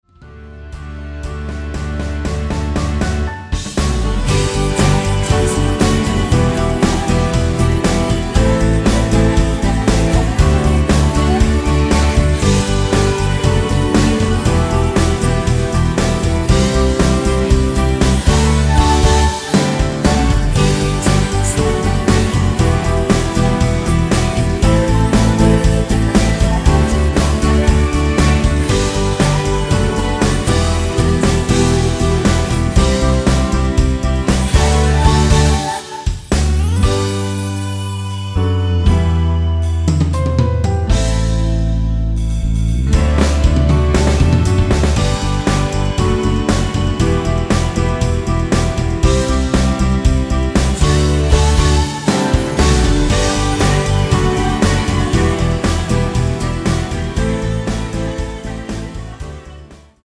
(-1) 내린코러스 포함된 MR 입니다.(미리듣기 참조)
◈ 곡명 옆 (-1)은 반음 내림, (+1)은 반음 올림 입니다.
앞부분30초, 뒷부분30초씩 편집해서 올려 드리고 있습니다.